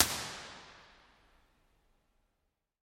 描述：Creating an Impulse Response from ZT Lunchbox Amplifier, recorded with Rode NT1000 microphone.
标签： Impulse Response amplifier IR
声道立体声